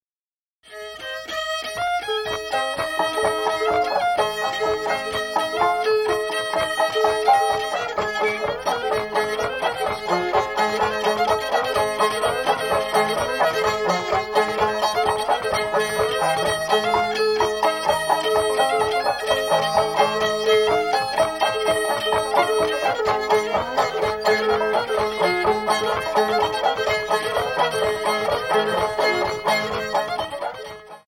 An archaic banjo-fiddle dance tune. Note the interplay between the two instruments.
fiddle